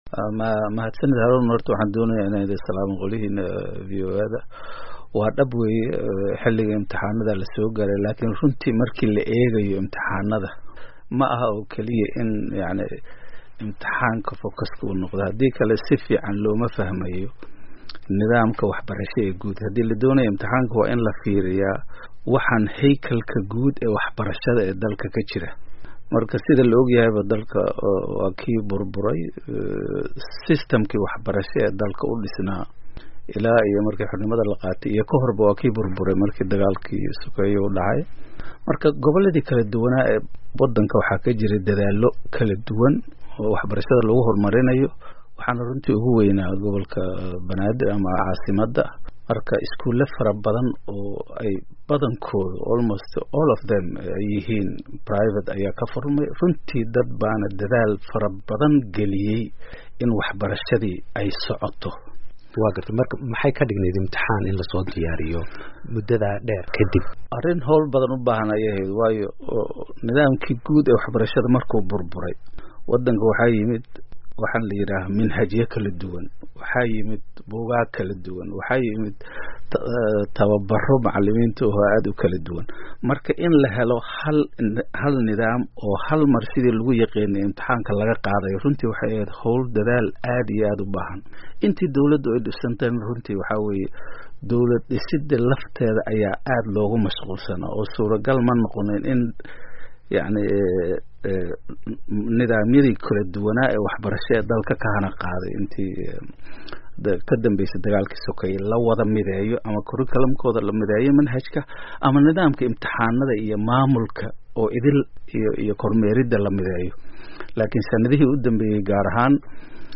Waraysi: Waxbarashada Soomaaliya